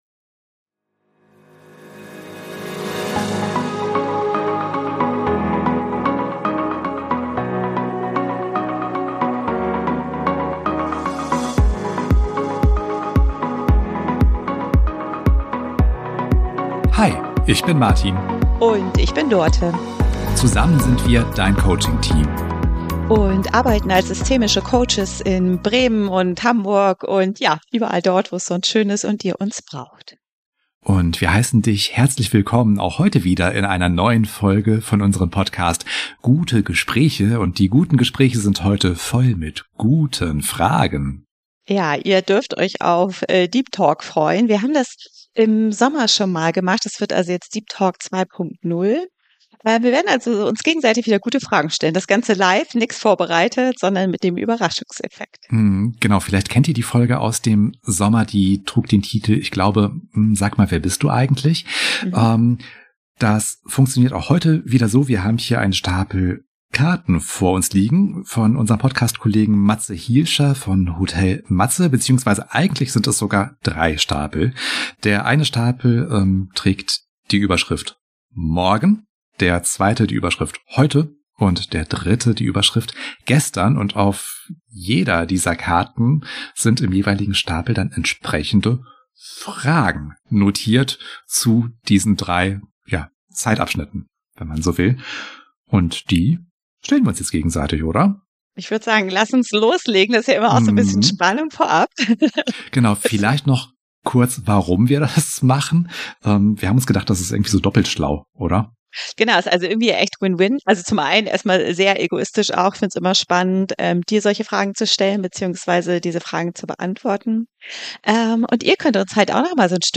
- In dieser Folge gehen wir erneut auf eine spannende Deep Talk-Reise – mit Fragen, die wir uns gegenseitig spontan stellen. Ohne Vorbereitung, aber mit viel Offenheit sprechen wir über prägende Ratschläge, unser zukünftiges Ich, Selbstfürsorge und die Kunst, auch im Testament Platz für Humor zu lassen.